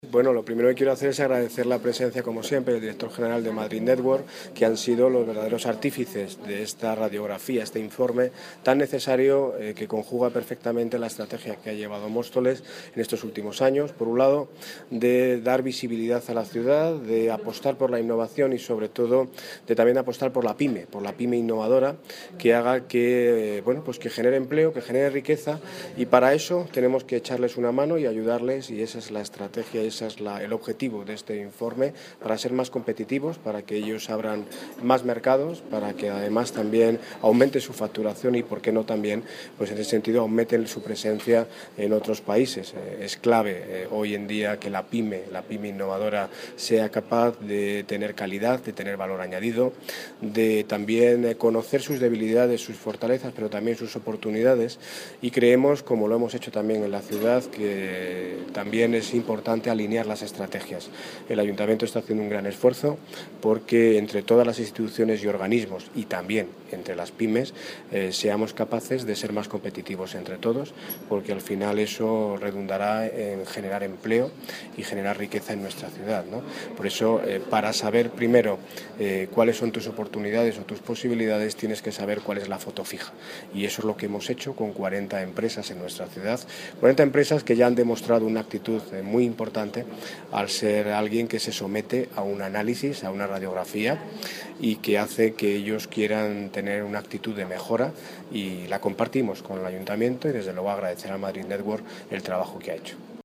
Audio - Ortiz (Daniel Ortiz (Alcalde de Móstoles) Sobre 40 Empresas Móstoles